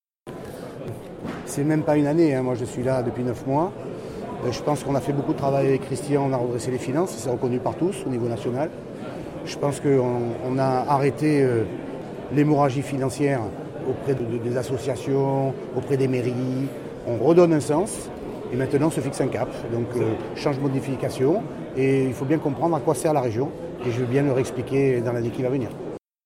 Les journalistes étaient présents en nombre pour les vœux à la presse de Renaud Muselier.